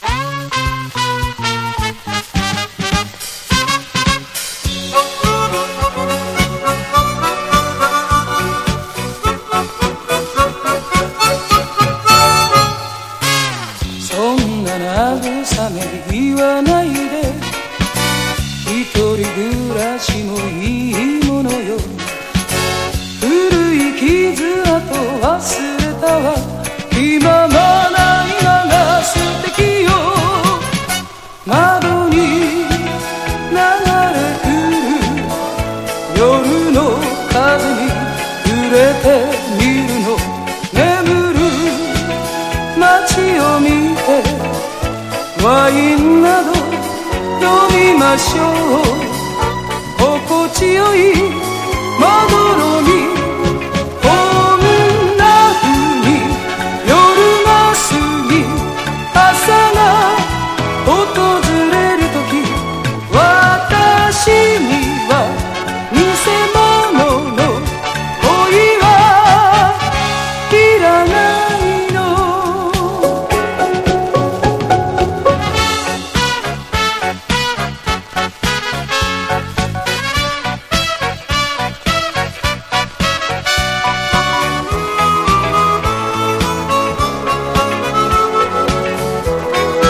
デビュー10周年記念作品としてリリースされた、込みあげ和ミディアム・ソウルフル・バラード。